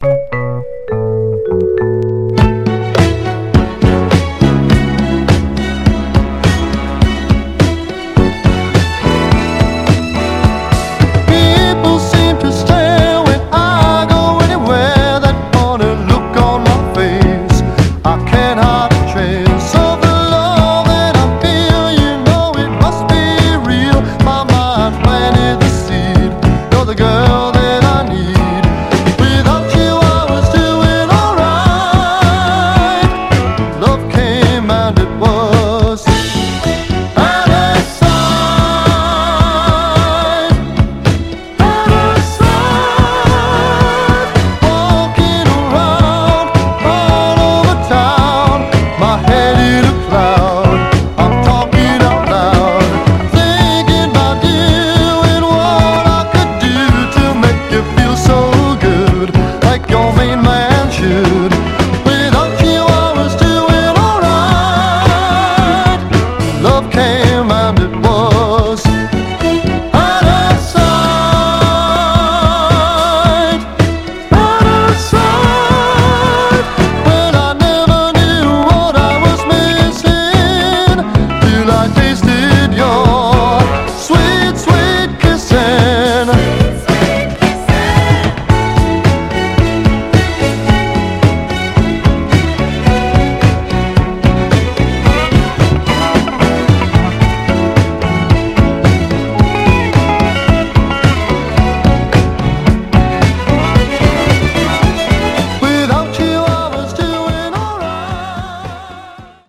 所々でプチっと鳴る箇所ありますが、音への影響は見た目の割に少なくプレイは大きな問題ありません。
※試聴音源は実際にお送りする商品から録音したものです※